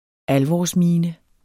Udtale [ ˈalvɒs- ]